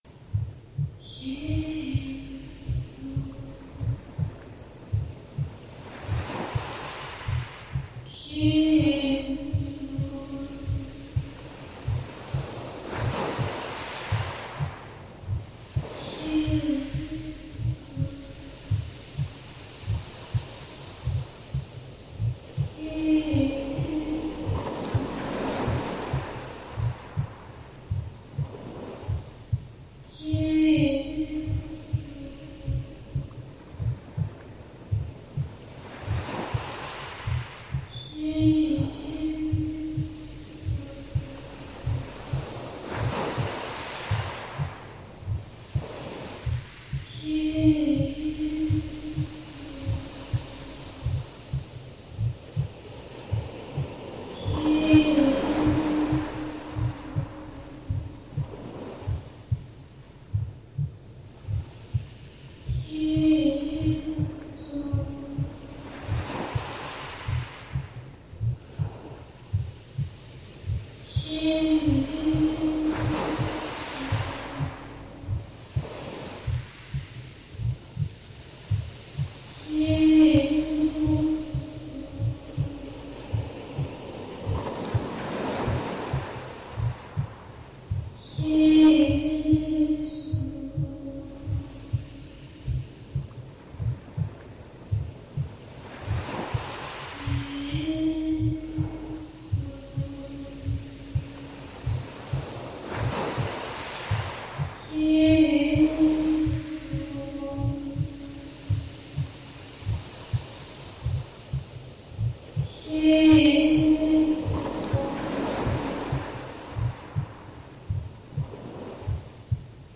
Meditation über den Namen JESUS (Soaking)
soaking-verliebe-dich-neu-in-jesus.mp3